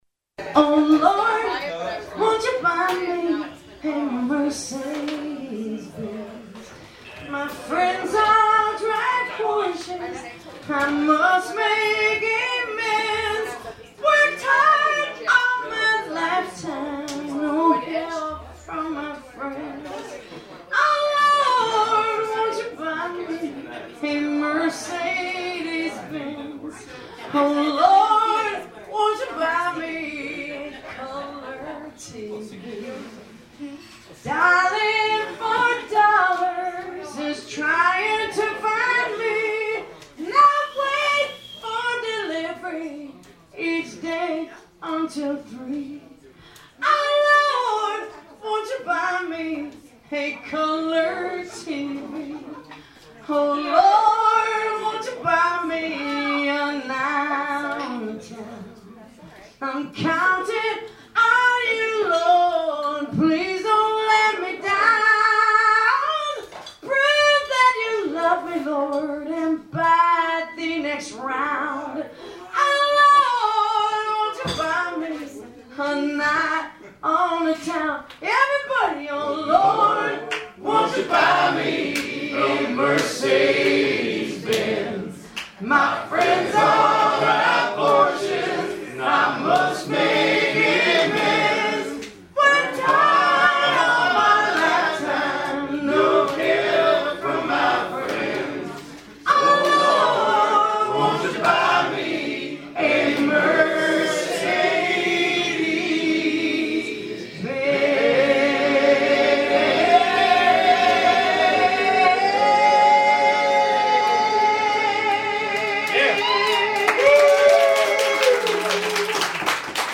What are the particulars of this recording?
Recorded live - always the crowd's favorite sing-a-long tune